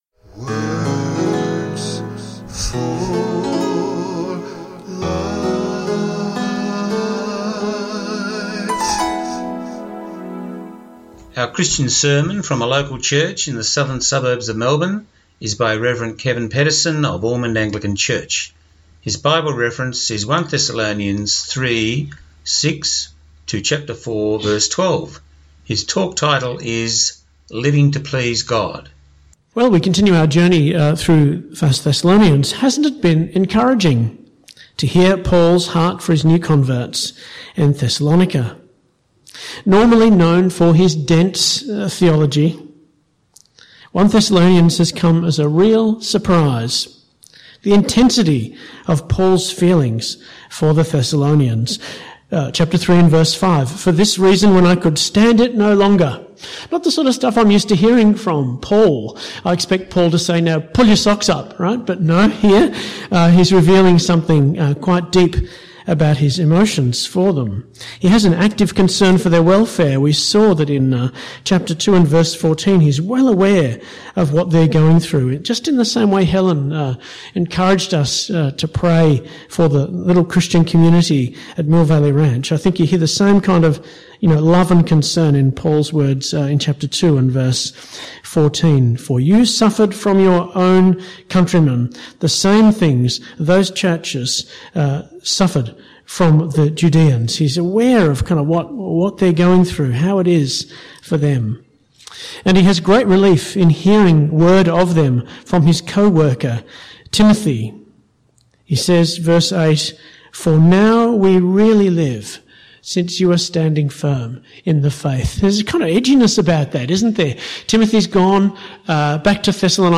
Christian sermon broadcast